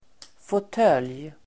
Ladda ner uttalet
fåtölj substantiv, easy chair , armchair Uttal: [fåt'öl:j] Böjningar: fåtöljen, fåtöljer Definition: ett slags bekväm stol Sammansättningar: skinnfåtölj (leather armchair), snurrfåtölj (swivel armchair)